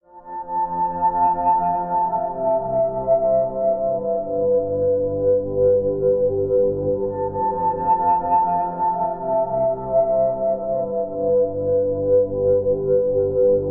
描述：落在一个杉木森林里的雪和雨在俄勒冈。大多数情况下它非常安静，但是在13:44，一些雪从麦克风旁边的一棵树上掉下来，非常响亮，所以要小心！
标签： 地理标记 冥想 氛围 环境 森林 梦幻般的 在外面 安静 自然 放松 现场记录 超声检查 表音文字
声道立体声